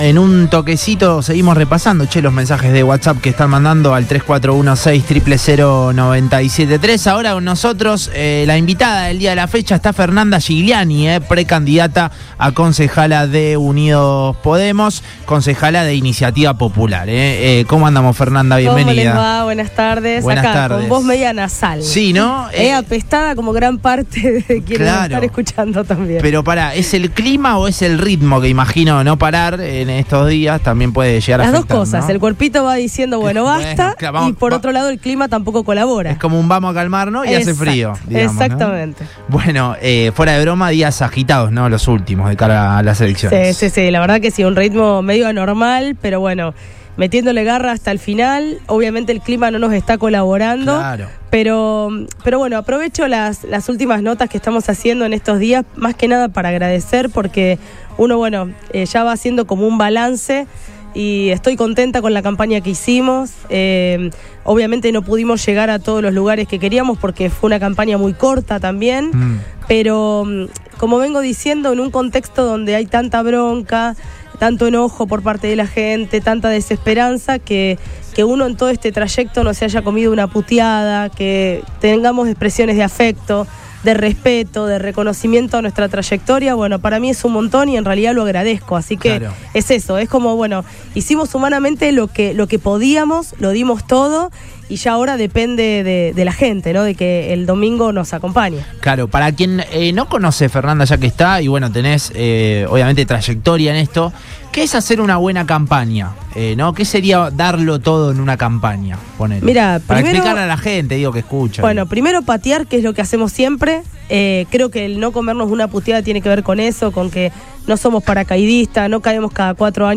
Fernanda Gigliani, precandidata a concejala de Unidos Podemos que buscará este domingo renovar su banca, pasó por Radio Boing y dialogó con Boing y Sus Secuaces.